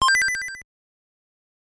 row_completion.wav